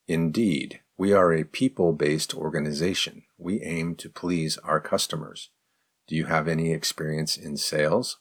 03_advanced_question_slow.mp3